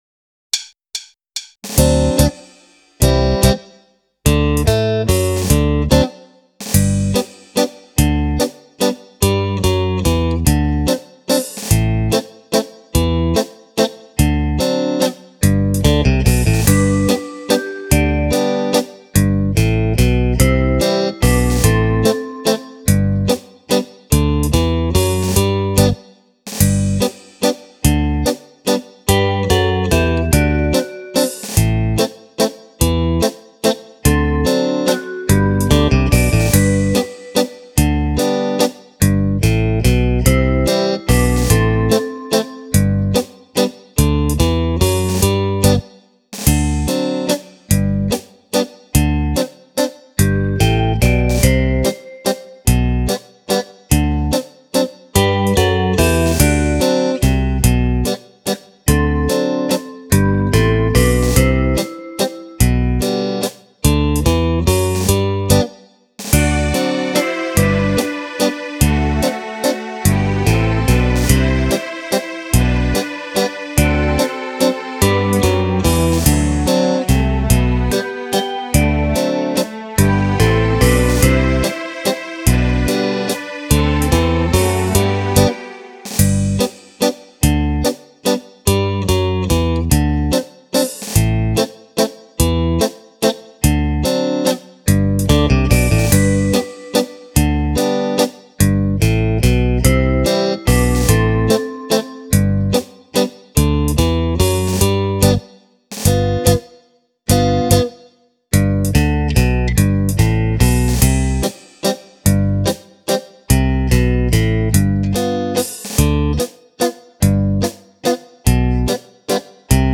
10 ballabili per Fisarmonica
Mazurka